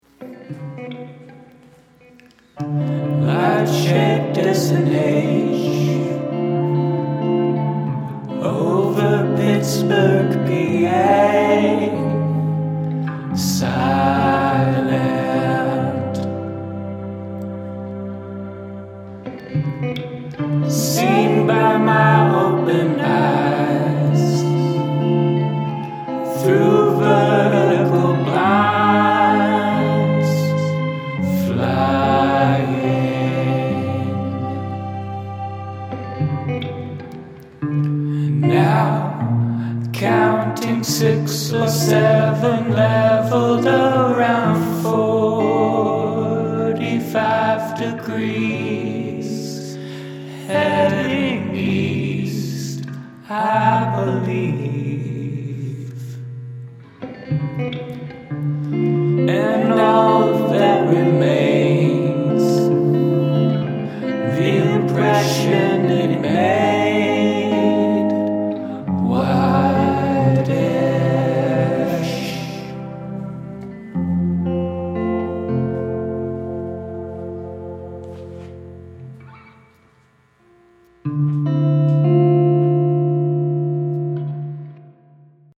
verse, verse, bridge, verse